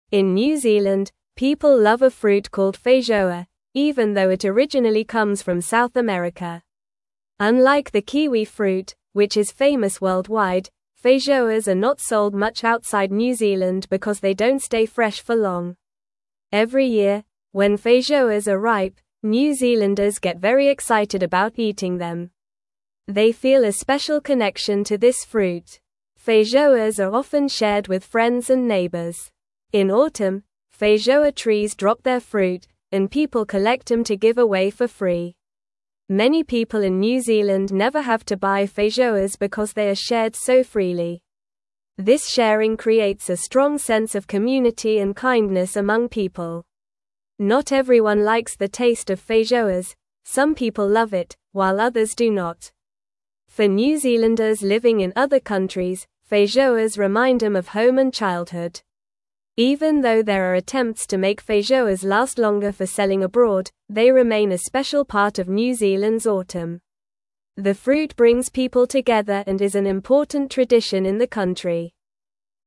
Normal
English-Newsroom-Lower-Intermediate-NORMAL-Reading-New-Zealands-Special-Fruit-The-Feijoa-Story.mp3